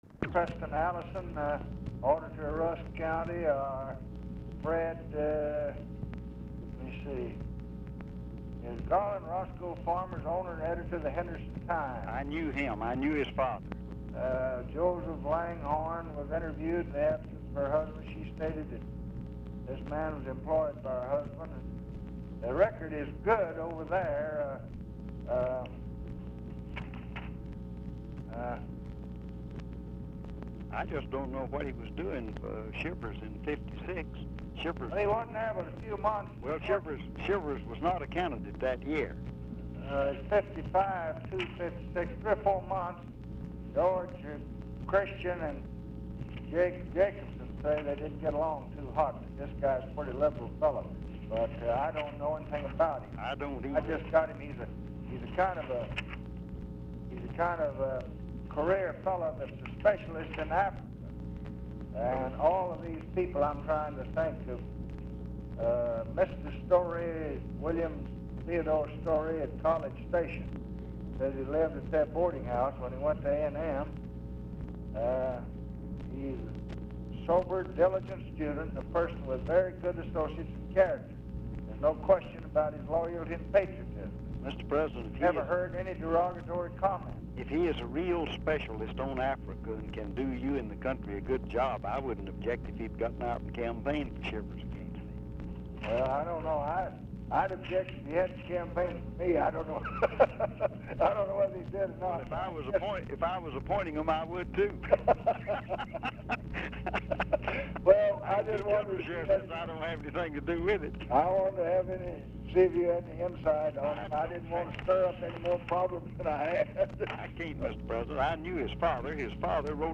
Telephone conversation # 11618, sound recording, LBJ and RALPH YARBOROUGH, 3/7/1967, 5:50PM | Discover LBJ
RECORDING STARTS AFTER CONVERSATION HAS BEGUN
Format Dictation belt
Location Of Speaker 1 Oval Office or unknown location